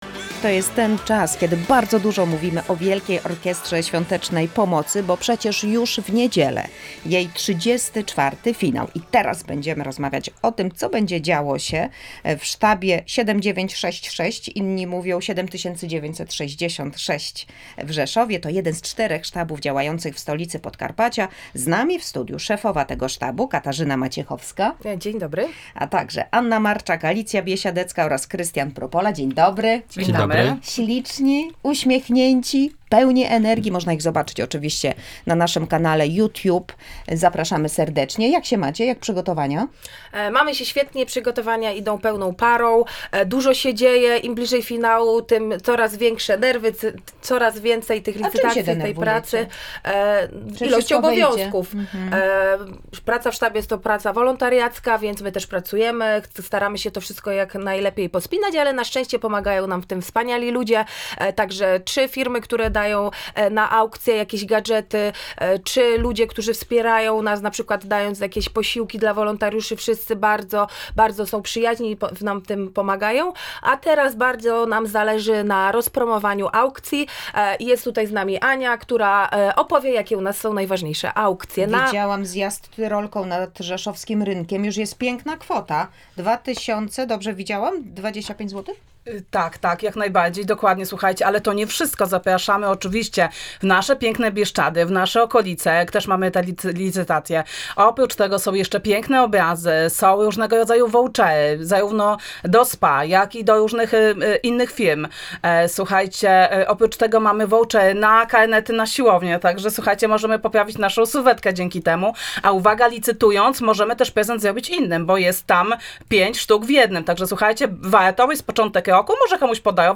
Rozmawialiśmy na żywo o tym co tak naprawdę jest ważnego w pomaganiu i dlaczego warto się jednoczyć.